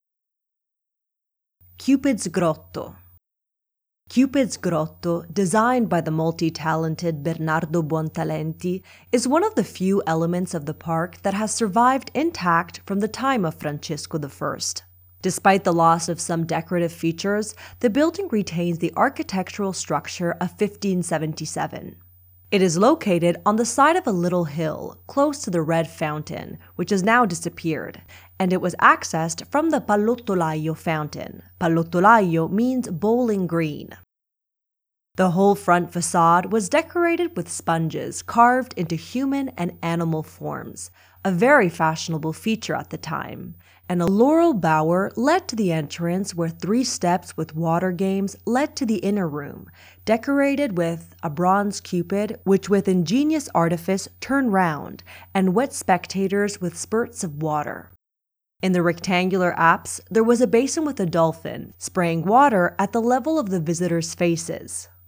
Audioguide of the Medici Park of Pratolino